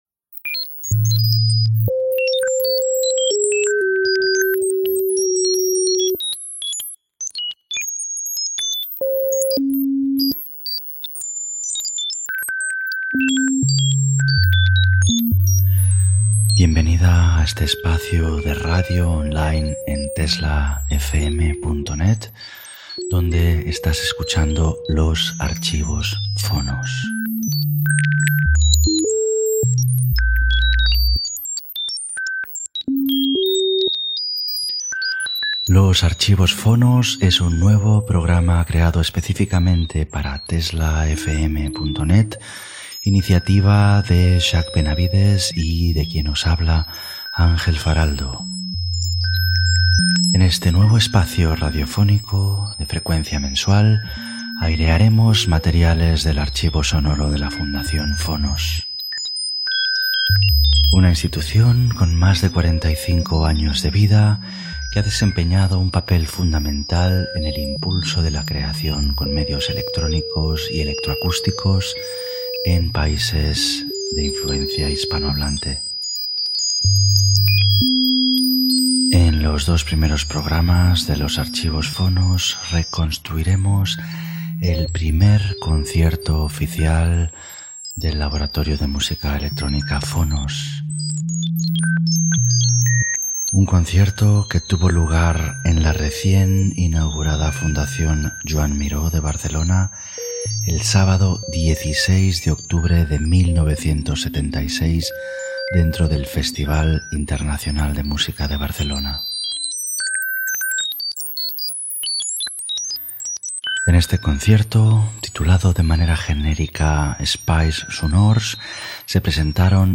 El primer concert experimental del Laboratori de Música Electrònica Phonos a l'any 1976 a la Fundació Joan Miró de Barcelona Gènere radiofònic Musical